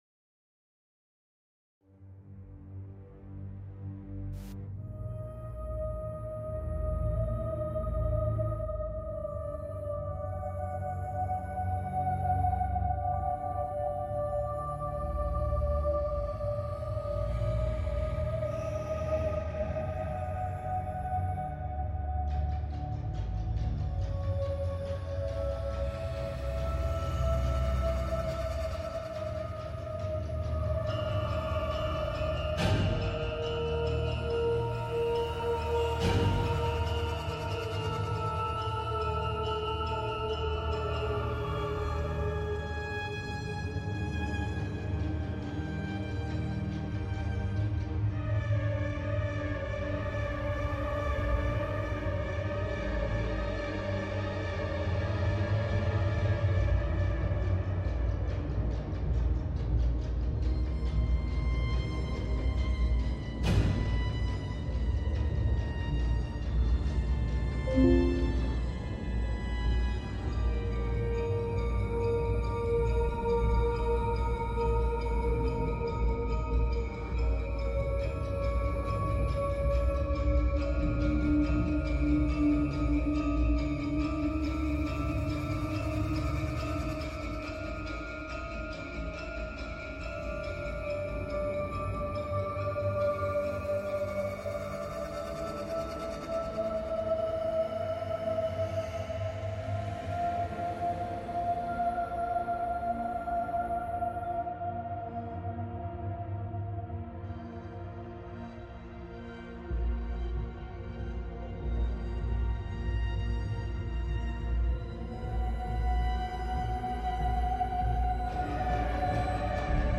Expect firsthand accounts, expert insights, and atmospheric storytelling that will leave you questioning what lurks in the unseen spaces beneath our everyday lives.